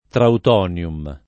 [ traut 0 n L um ]